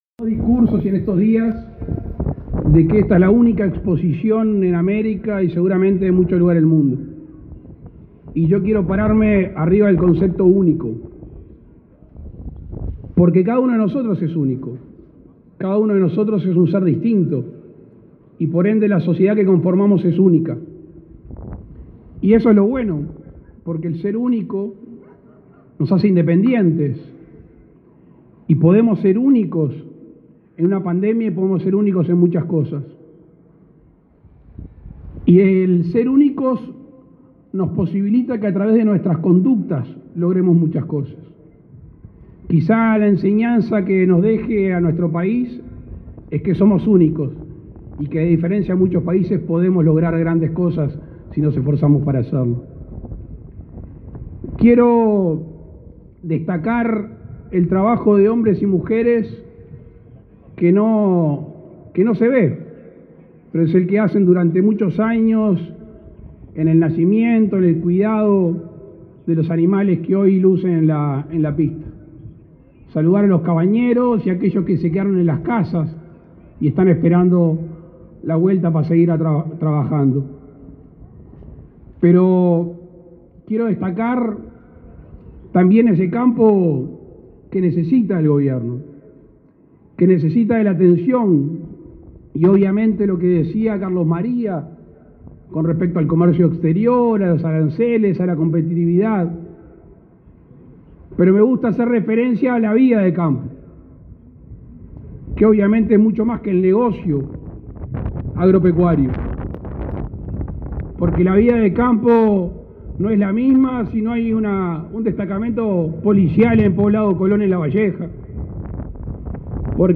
Lacalle Pou en acto de clausura de Expo Prado 2020: "Yo estoy con el campo" - 970 Universal
«Yo estoy con el campo», concluyó en su breve discurso.